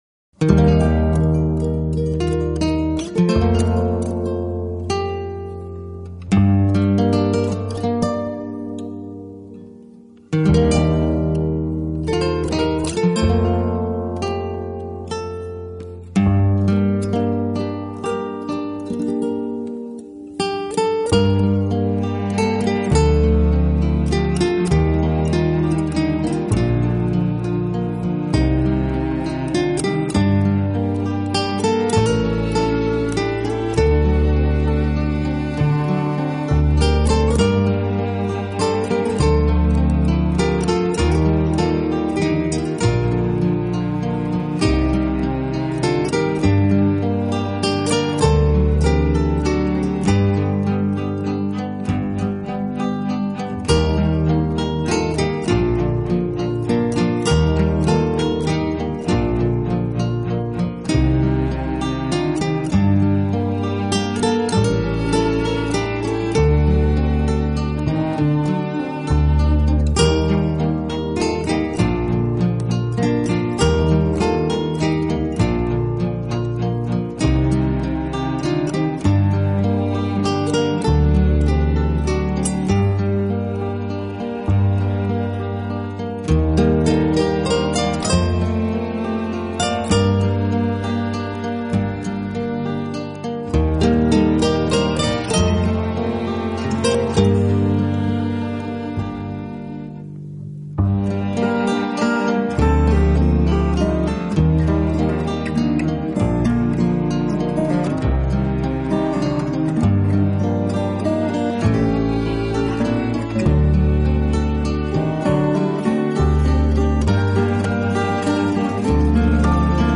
运用高超的吉他技巧，展现了浪漫热情的情调
他的第六张专辑中大部分乐曲为新弗拉明戈风 格，是他精湛技艺的体现。